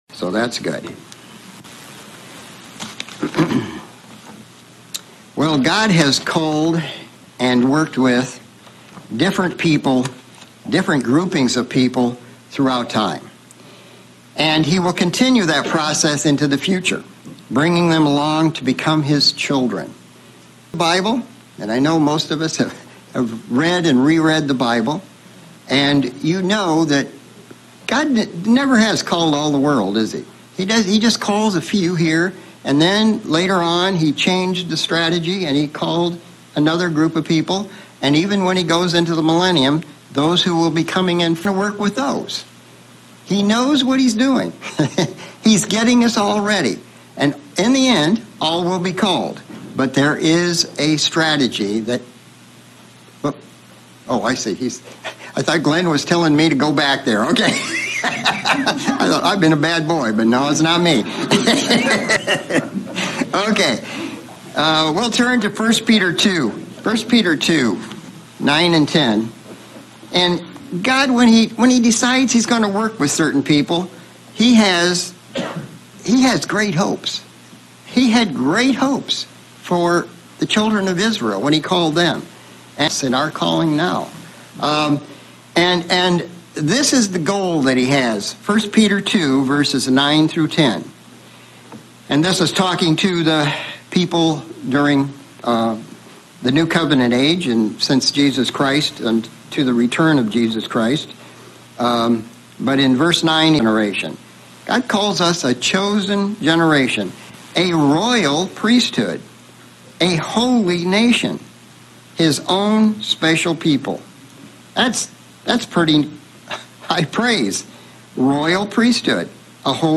Sermon looking at how God has worked, and will work with Israel thru time: A - Ancient Israel B -HIs church C - The Millennium This sermon looks at how God worked with each group and what the promises are for their future.